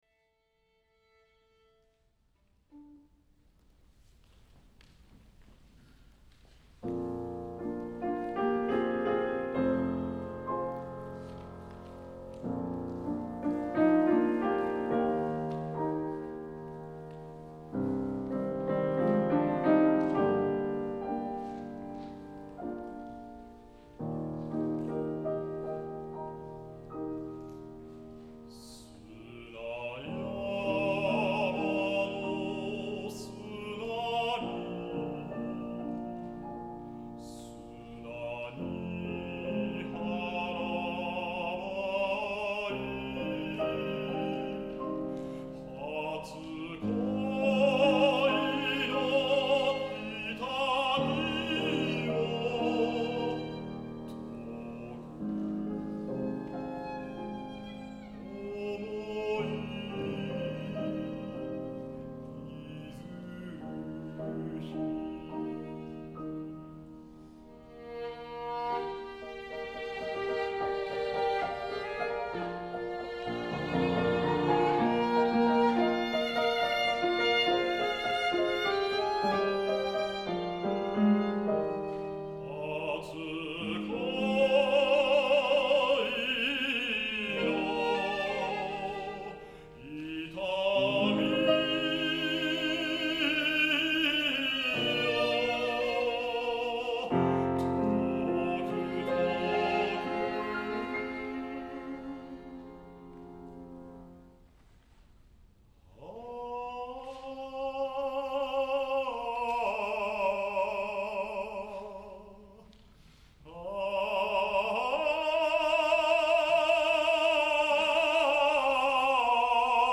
バリトン
Bariton